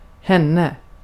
Ääntäminen
IPA: [hæn]